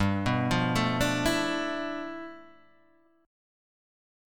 G 13th